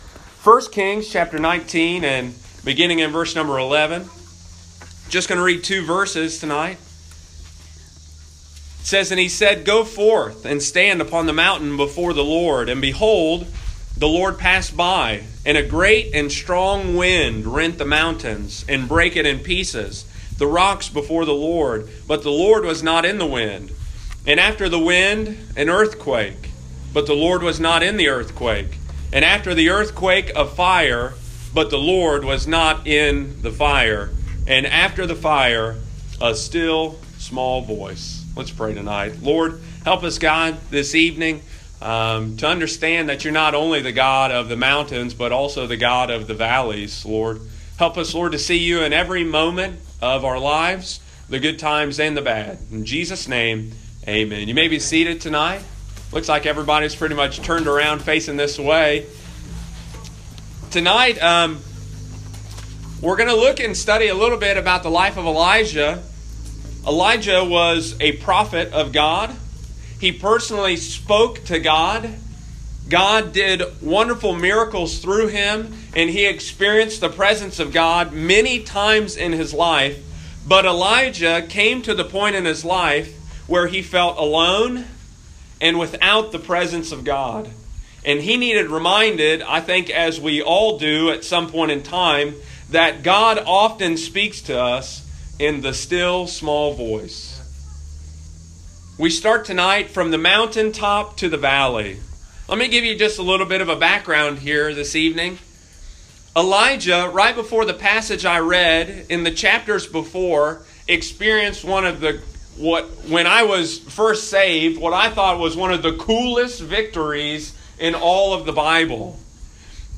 preaches at the Hargus Lake service on Sunday evening, August 19.